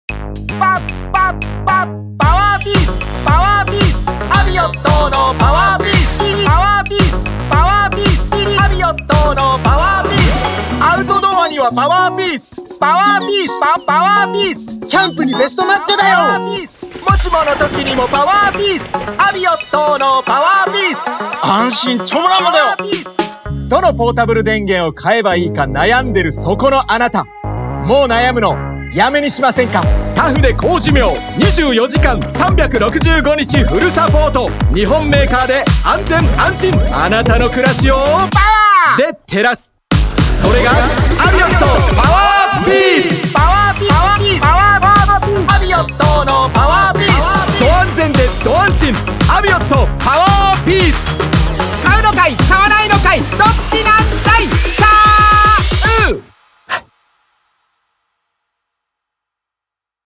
SONG ELECTRO
シンプルな構造の中でフックを際立たせながら、繰り返し聴きたくなるような軽快さと勢いを意識しました。